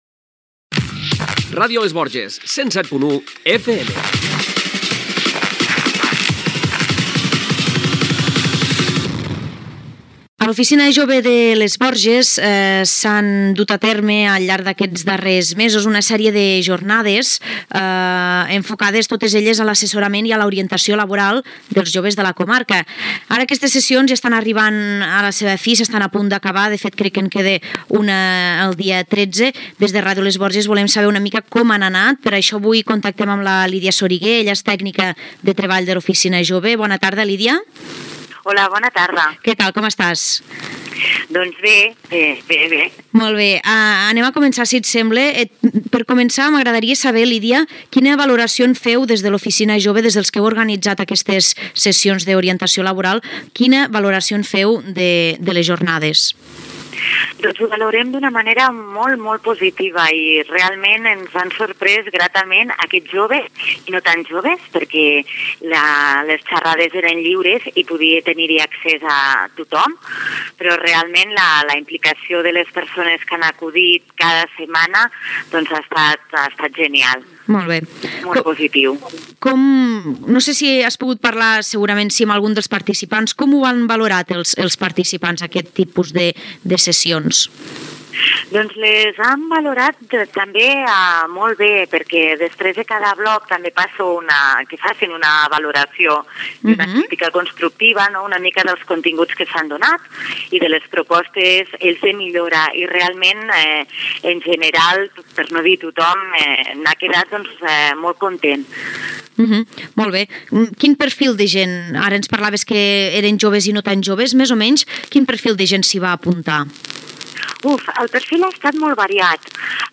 Indicatiu de l'emissora. El cicle de jornades d'assessorament i orientació laboral fetes a l'Oficina Jove de Les Borges Blanques.
Info-entreteniment